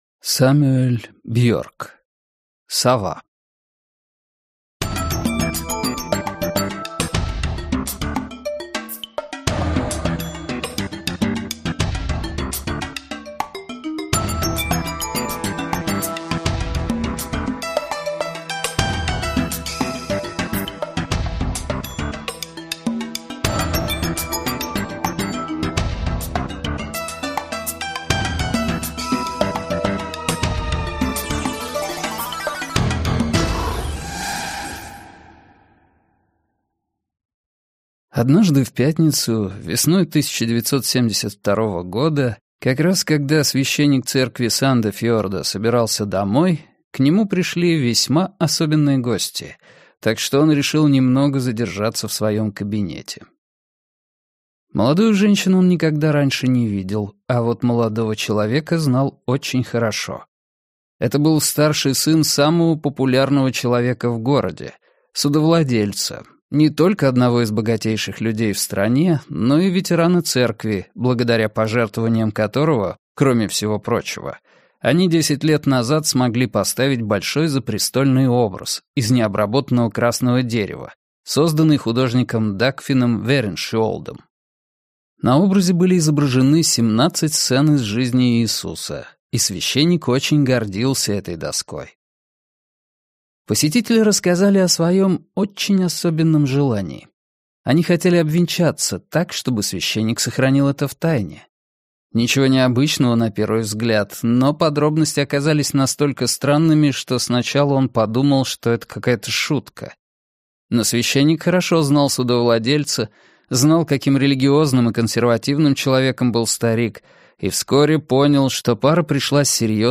Аудиокнига Сова - купить, скачать и слушать онлайн | КнигоПоиск